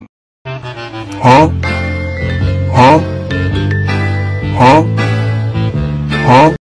Goofy Ahh Music Huh